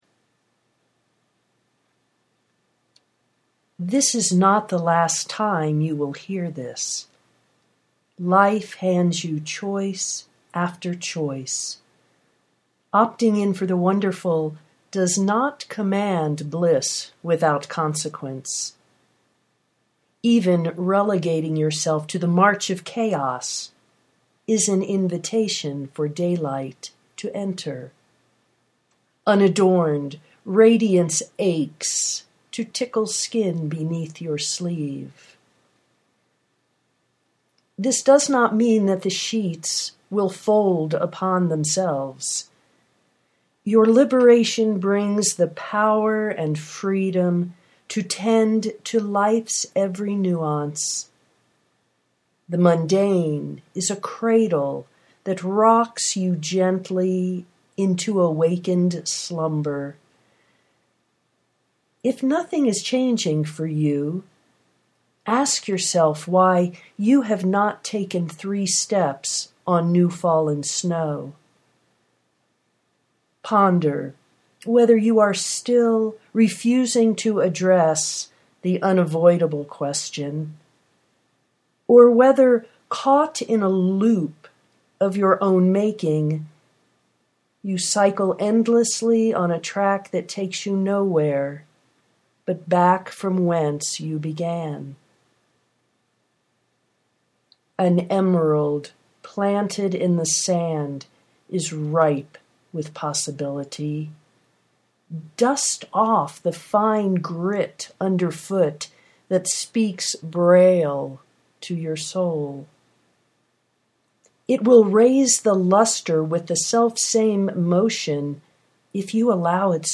you were born to sing a song of uncommon melody (audio poetry 4:29)